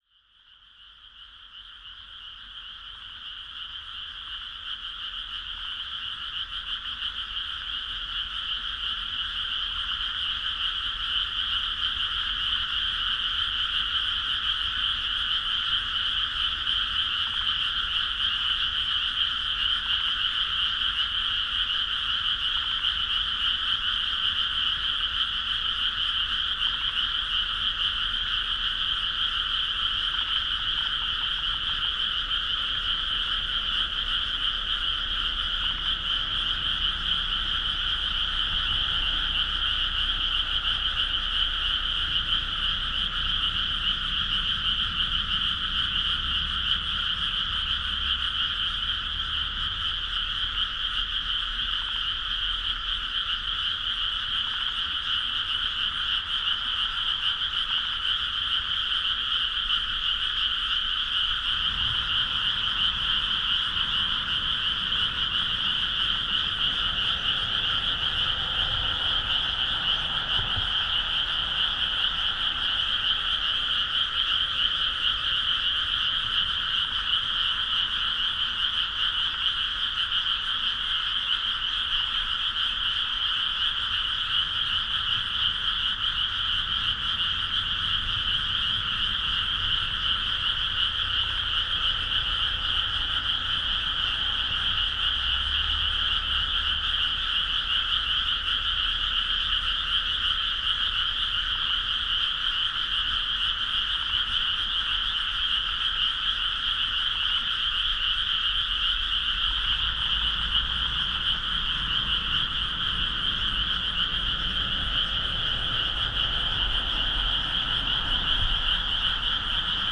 Edirol R-09, Telinga DAT Stereo 2008年8月8日 山形県酒田市
赤川河口の草地にて。
この日の録音ではハマナスやグミの藪で鳴いている一匹にマイクを近づけた記憶です。
マイクはパラボラをはずしたTelinga DAT Stereo。
無指向性のステレオは当時から私好み。結構いい音を残しているなあと感心します。